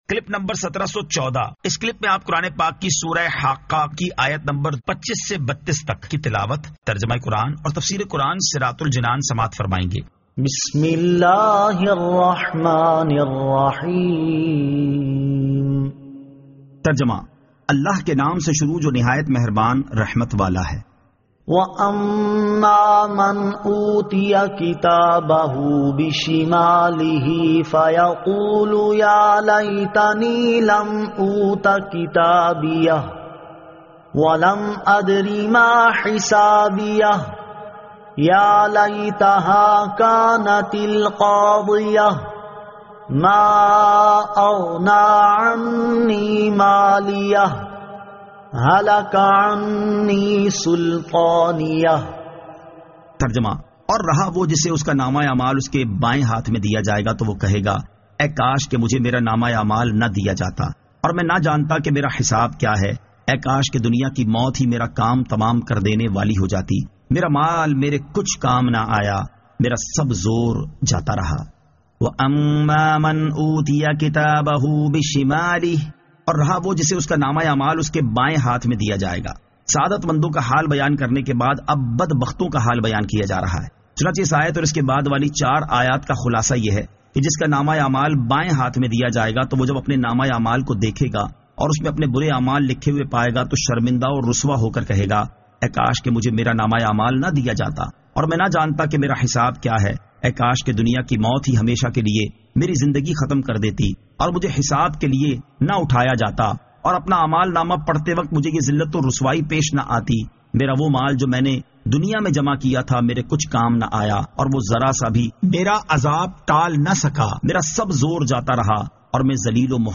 Surah Al-Haqqah 25 To 32 Tilawat , Tarjama , Tafseer